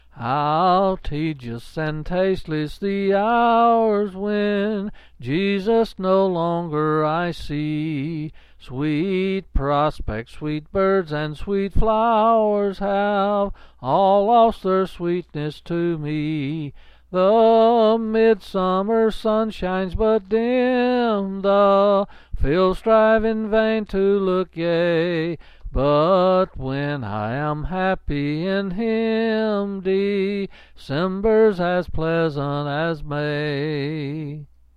Quill Pin Selected Hymn